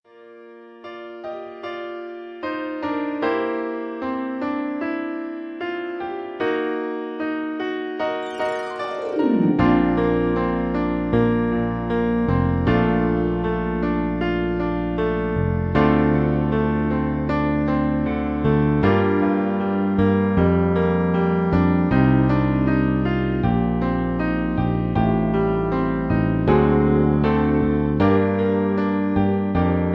backing tracks
easy listening, love songs